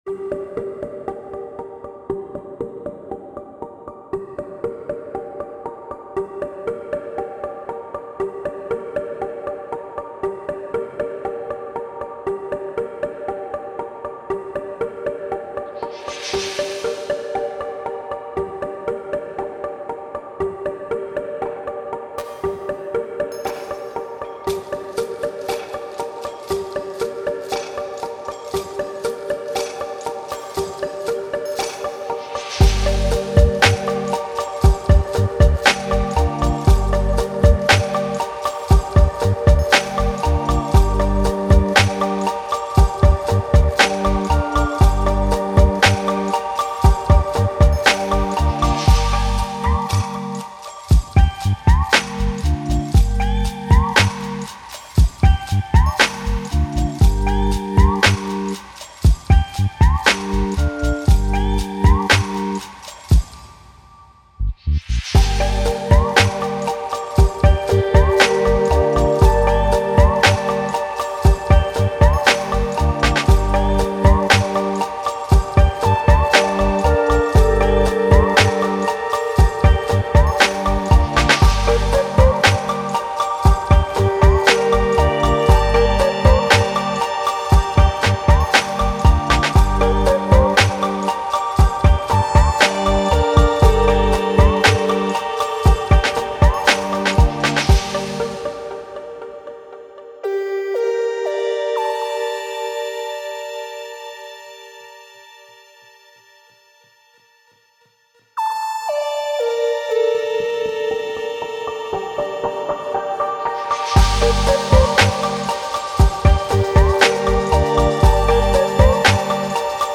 Gritty broken beats are forever dropping.